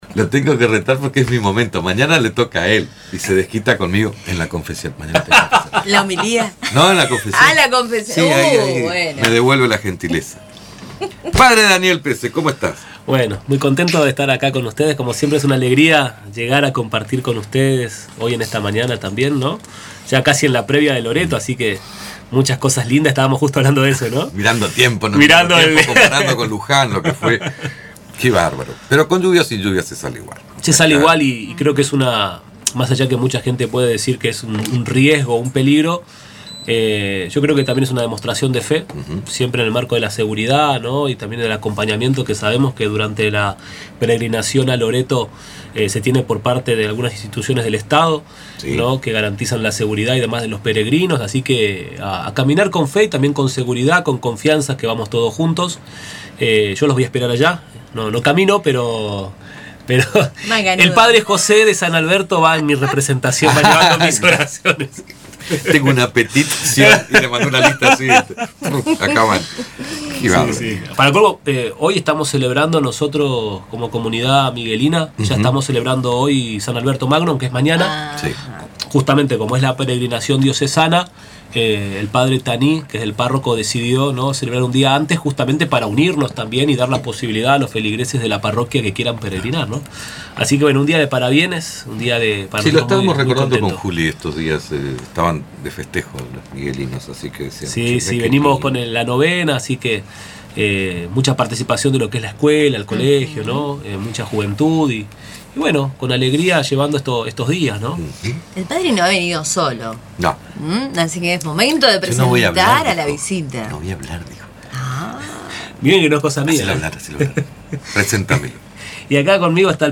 Durante la entrevista